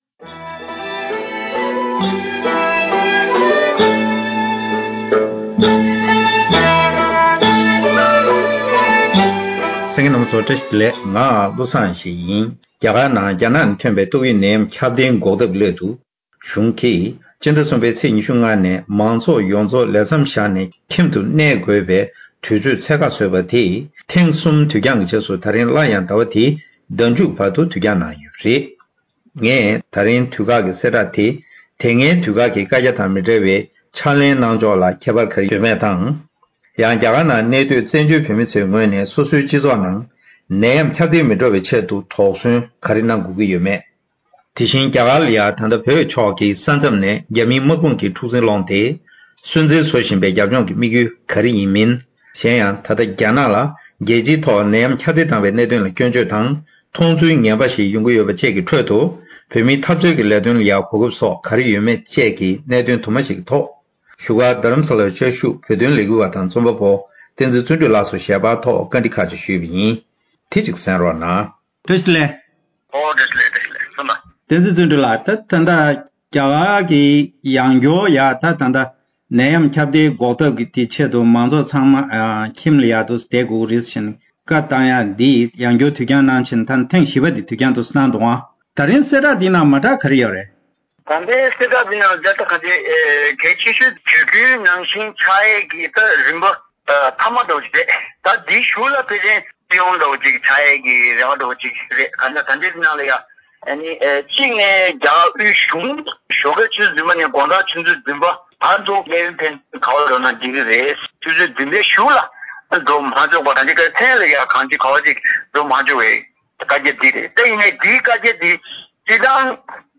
བཀའ་མོལ་ཞུས་པར་གསན་རོགས་ཞུ།།